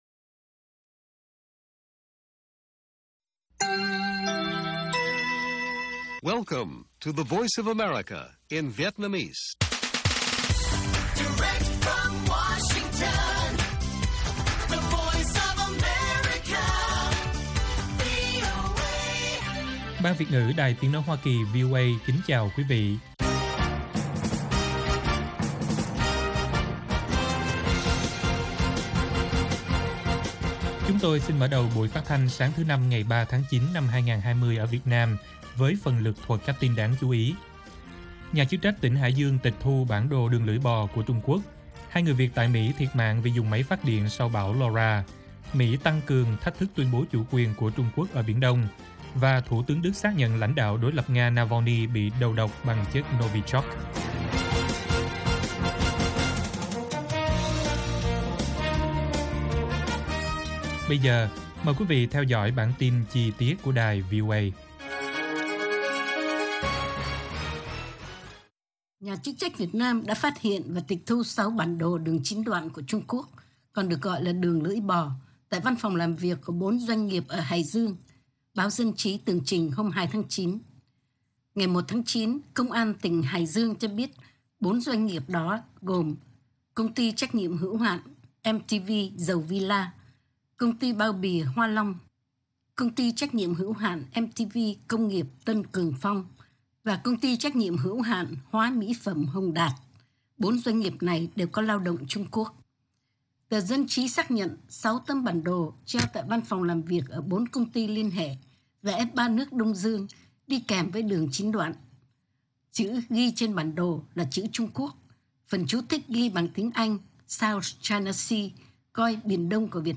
Bản tin VOA ngày 3/9/2020